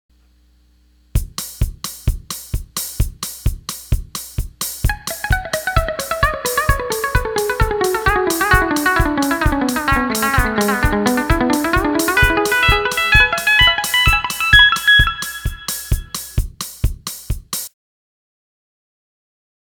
Fast Echo Doubling Riff : The Steel Guitar Forum
First solo
Fast Echo Muted Tab 1.wma